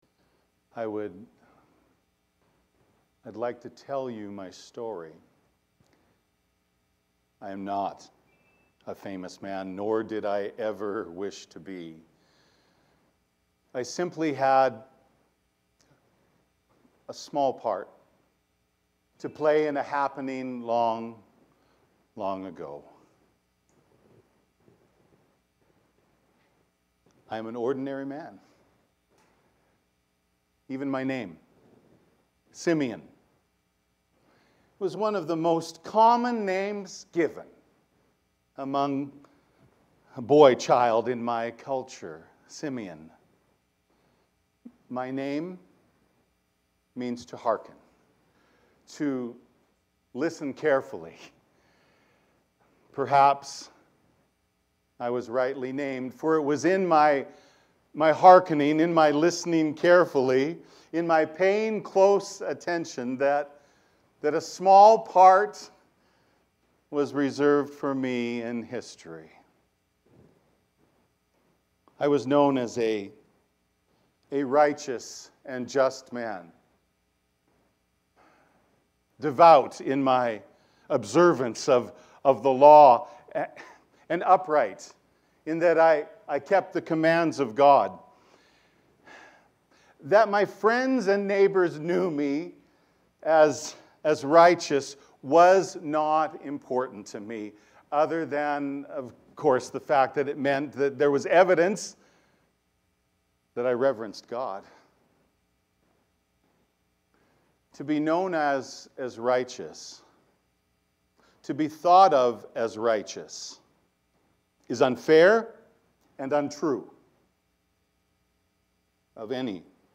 Christmas Eve Monologue - "Simeon"